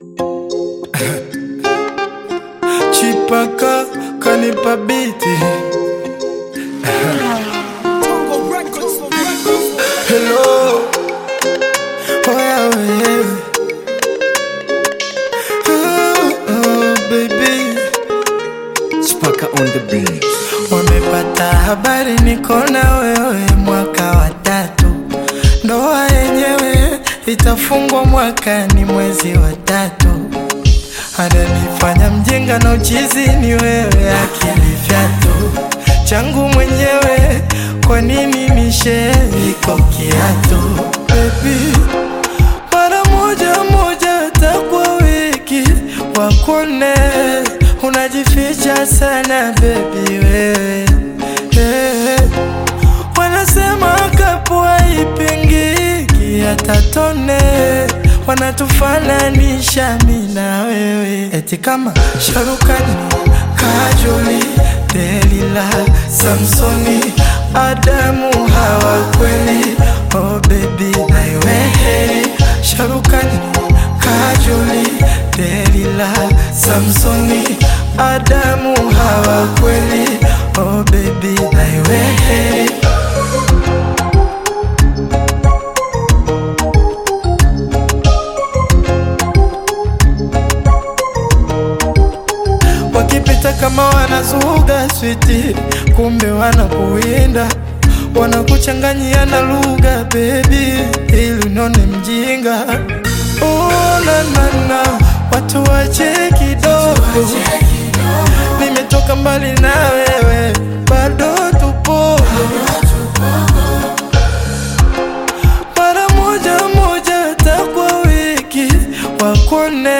Bongo Flava music track
This catchy new song